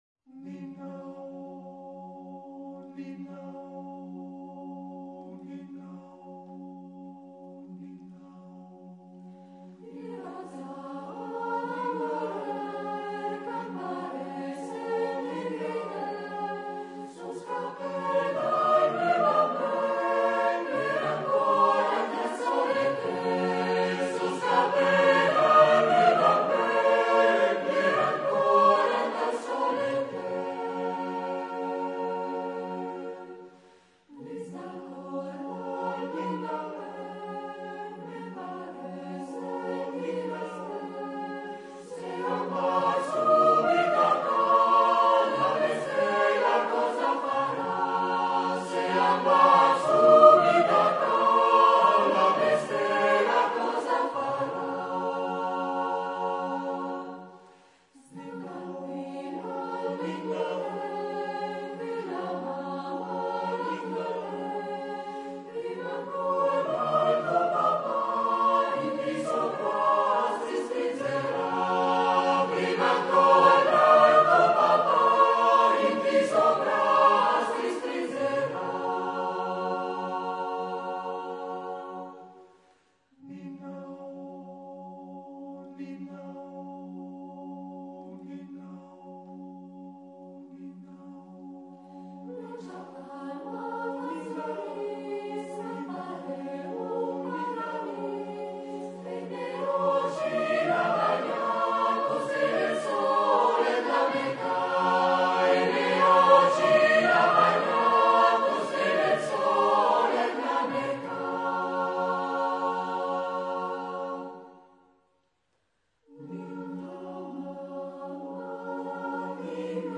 GenereCori